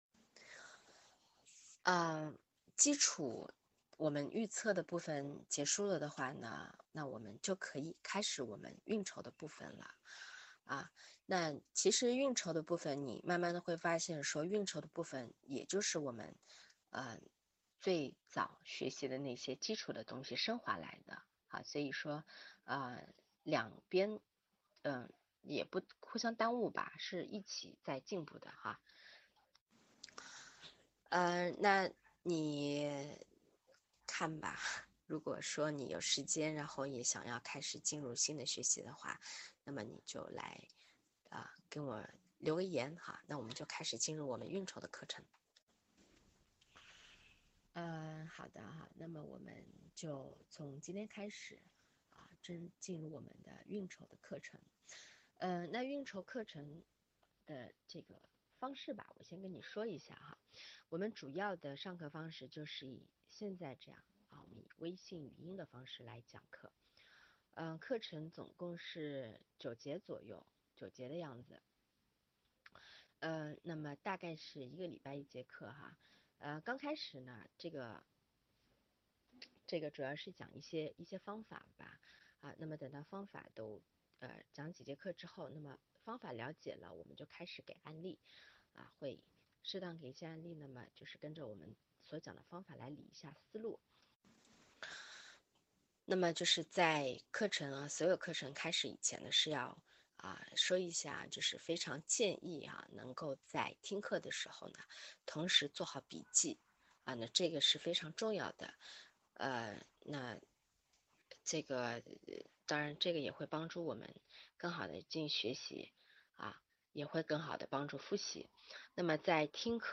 清晰录音